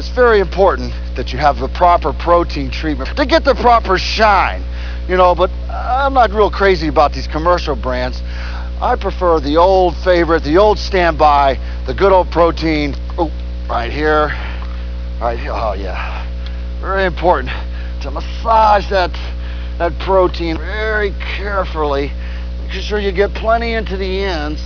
He was so popular, in fact, that the WWF decided to reward him by giving him his own Piper’s Pit style talk show, The Barber Shop.